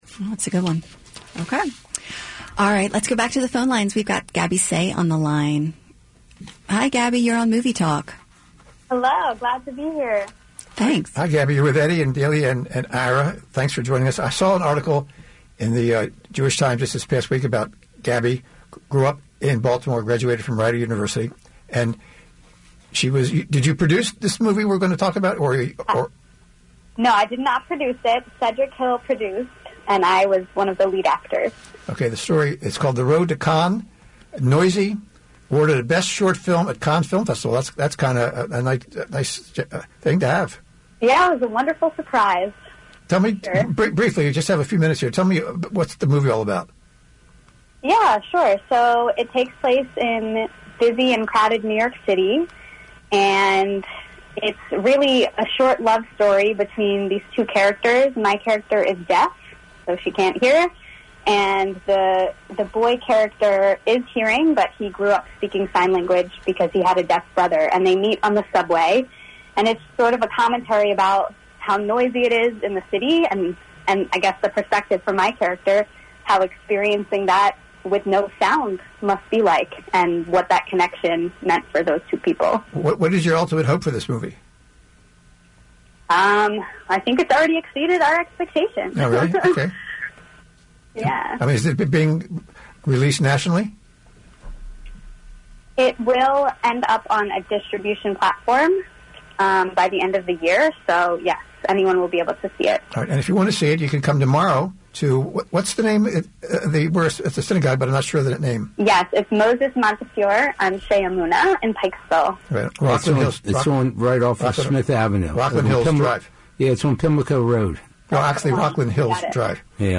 Noisy Interview on WLS690